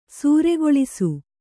♪ sūregoḷisu